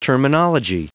Prononciation du mot terminology en anglais (fichier audio)